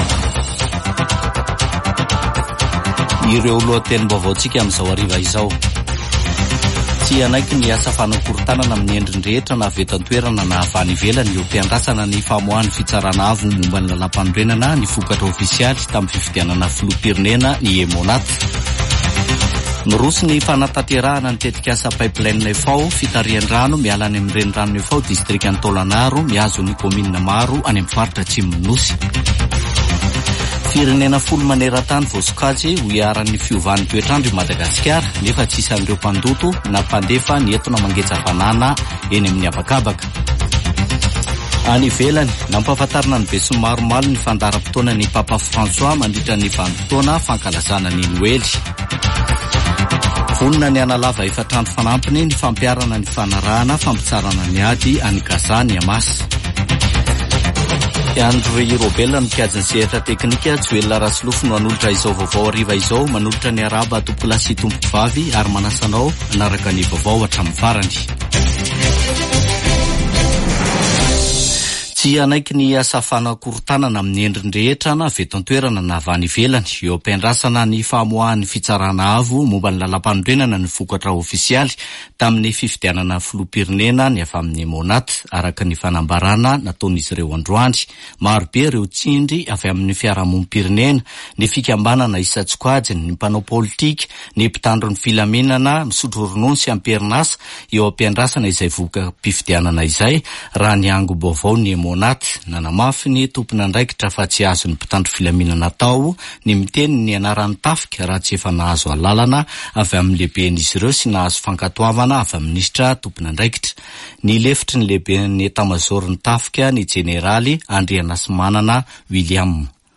[Vaovao hariva] Alarobia 29 nôvambra 2023